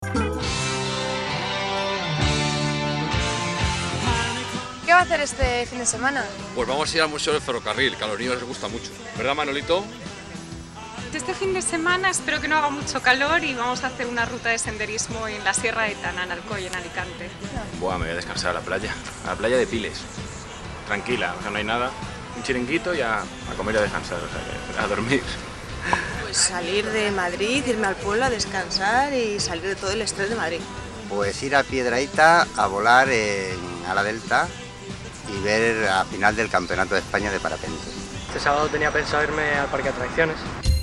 Preguntan a seis personas dónde pasan el fin de semana.
En el reportaje vemos a seis personas que nos hablan de lo que harán este fin de semana.